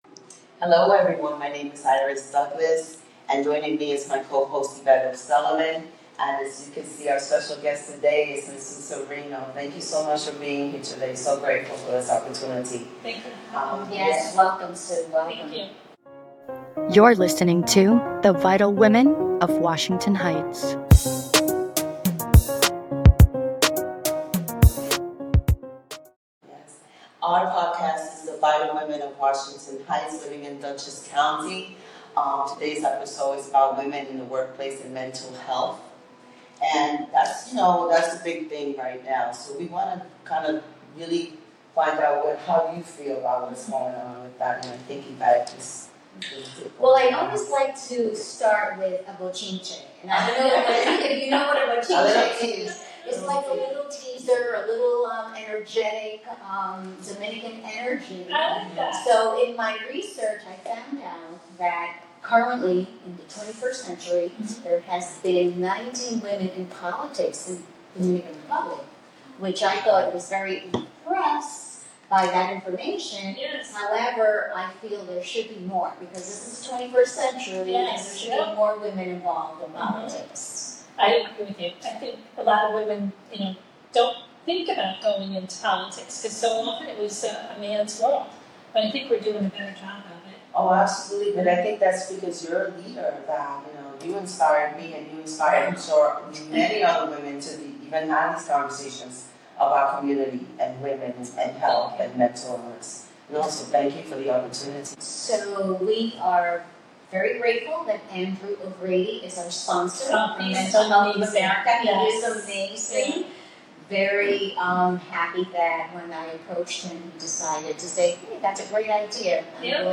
The three women have a conversation regarding women in entry-level leadership roles and women over the age of 40 pursuing entrepreneurship. There were also conversations around the importance of mental health awareness, the impact of personal stories, and the need for more women in leadership roles.